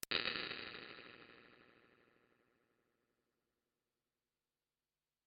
吉他踏板的脉冲响应 " 圣杯厅4大
描述：这些声音是通过录制一个或多个吉他效果踏板的输出并输入10ms白噪声脉冲而产生的。如果踏板是单声道，则样本也是如此，反之亦然。
声道立体声